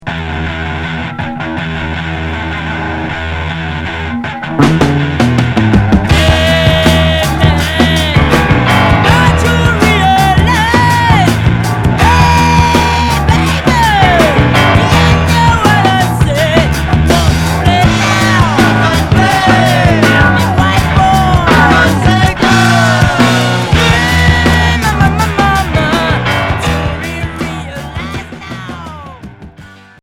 Heavy rock pop Premier 45t retour à l'accueil